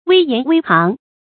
危言危行 注音： ㄨㄟ ㄧㄢˊ ㄨㄟ ㄒㄧㄥˊ 讀音讀法： 意思解釋： 危：正直。說正直的話，做正直的事。